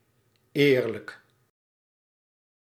Ääntäminen
Tuntematon aksentti: IPA: /ɐtˈkrɨtɨj/